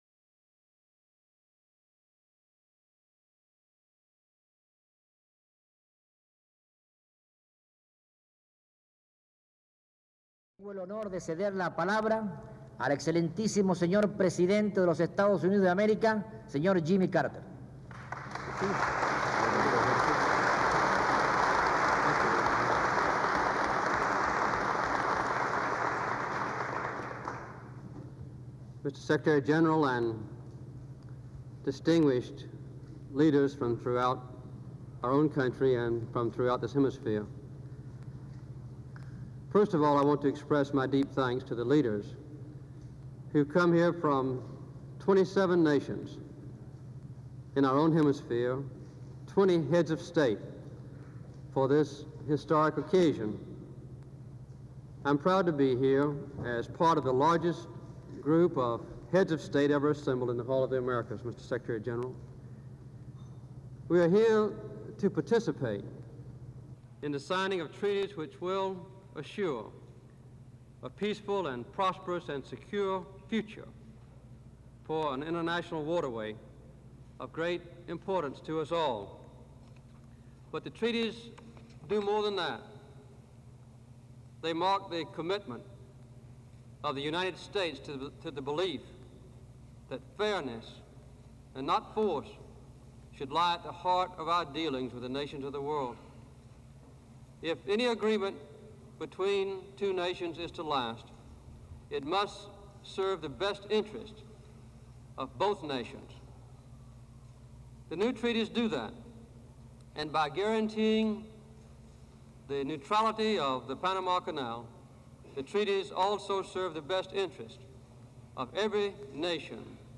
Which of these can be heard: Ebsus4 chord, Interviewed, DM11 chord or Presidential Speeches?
Presidential Speeches